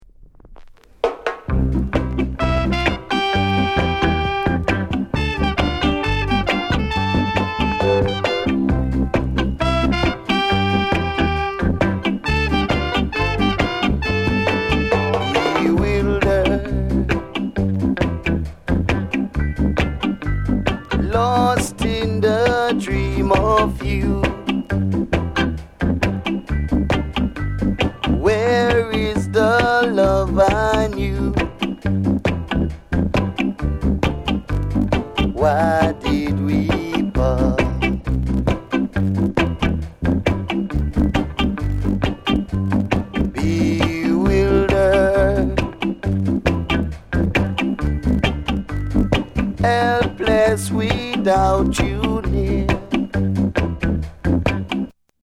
RARE REGGAE